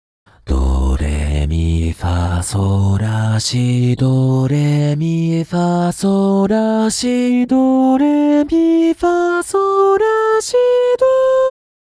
【説明】 ： C4以上が裏声になる音源です。
urrと比較するとやや落ち着きのある音源です